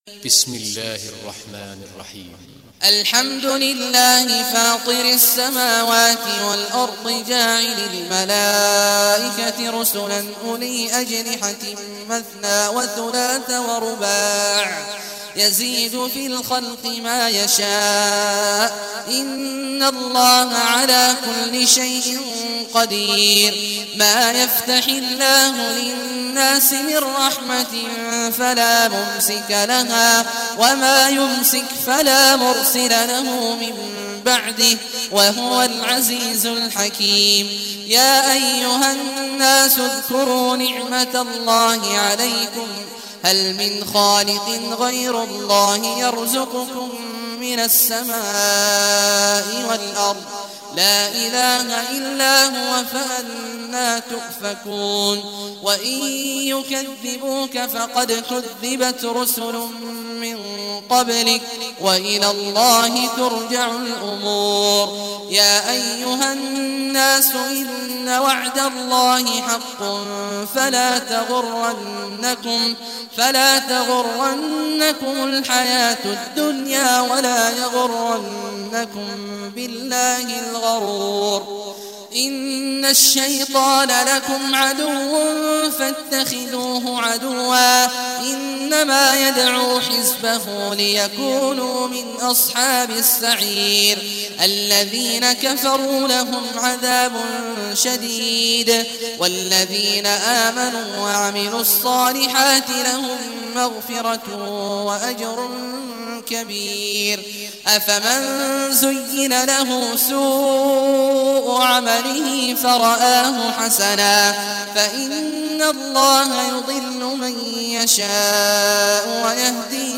Surah Fatir Recitation by Sheikh Awad al Juhany
Surah Fatir, listen or play online mp3 tilawat / recitation in Arabic in the beautiful voice of Sheikh Abdullah Awad al Juhany.